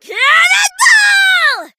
bonni_ulti_vo_02.ogg